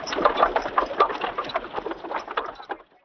wagon3.wav